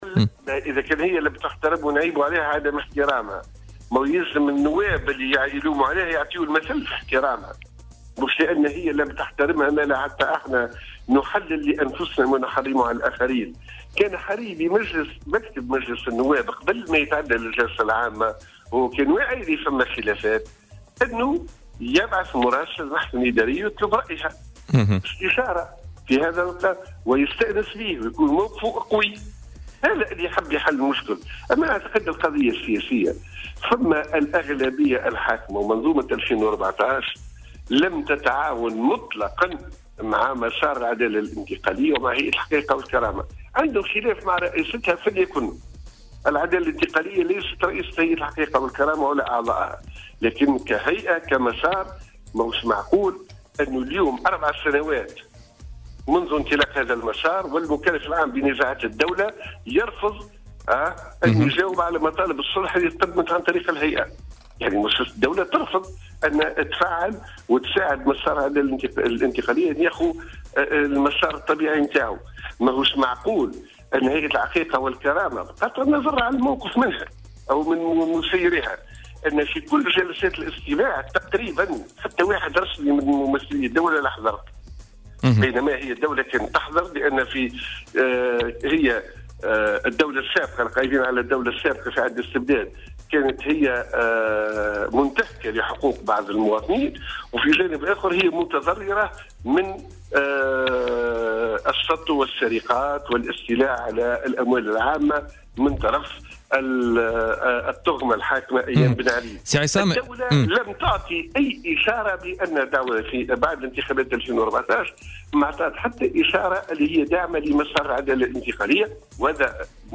وأوضح في اتصال مع "بوليتيكا" على "الجوهرة أف أم" أن المحكمة الإدارية هي المخولة حاليا لتأويل النص القانوني.